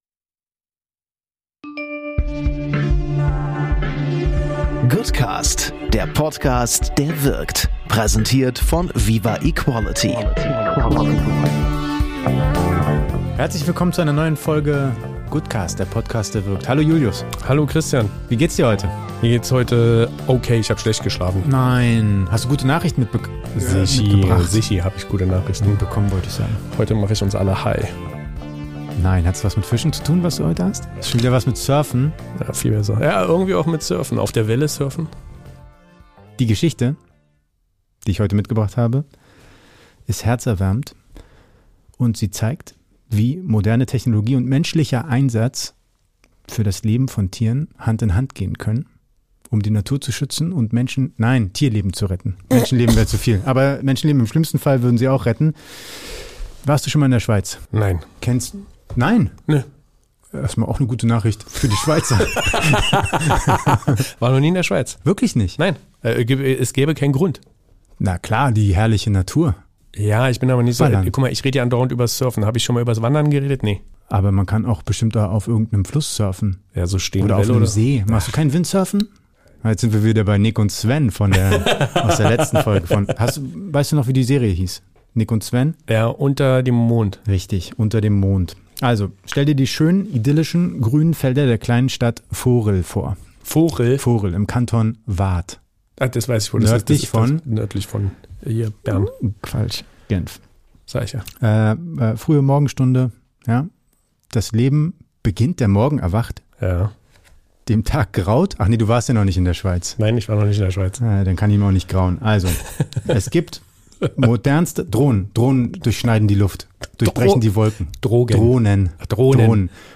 In der neusten Ausgabe der Goodnews ballern unsere Hosts, wie gewohnt, extrem bunte News aus aller Welt. Von Maryland bis Europa ist alles dabei.